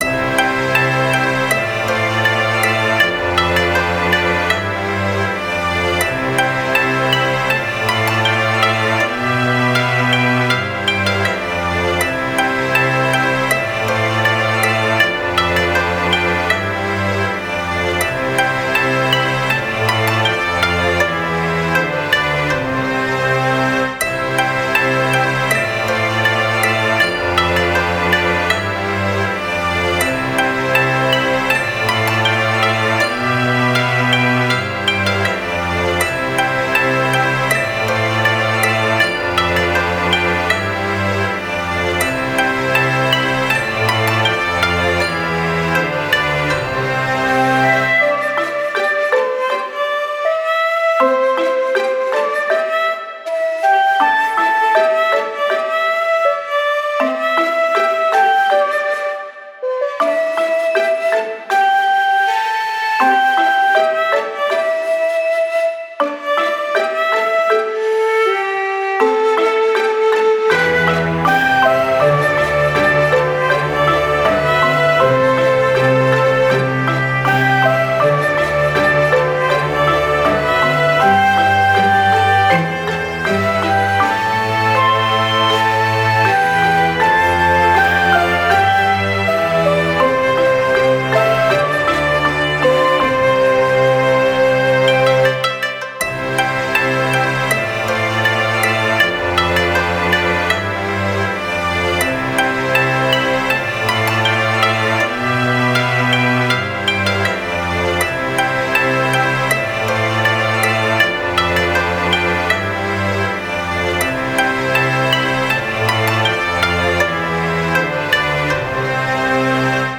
ストリングス尺八などの和楽器とオーケストラサウンドが重なり合う、幻想的で心癒される和風BGMです。
そよ風が草原を渡るように静かに始まり、徐々に広がっていく音の風景は、日本の自然や四季の美しさを感じさせます。
• ジャンル：和風BGM / 癒し / 自然 / 和楽器 × オーケストラ
• 雰囲気：幻想的 / 落ち着き / 優しさ / 静けさ / 日本的 / 風景音楽